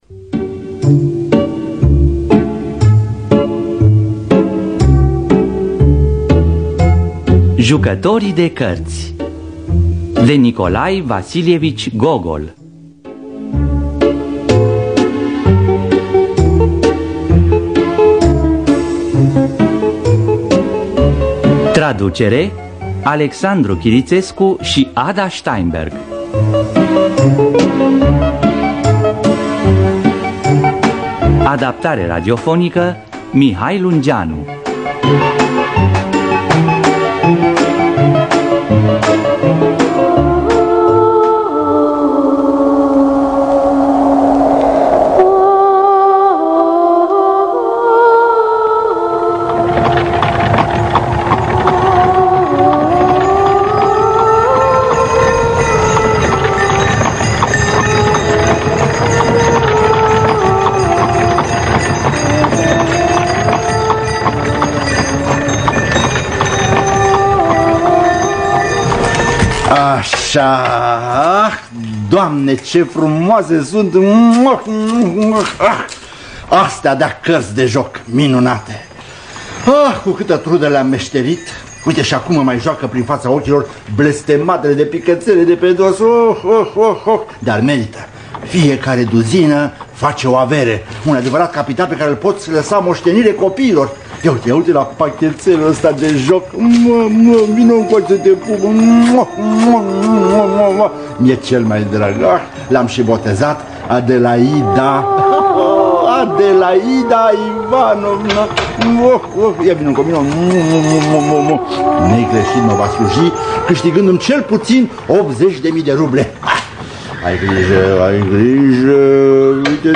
Adaptarea radiofonică
Înregistrare din anul 1998.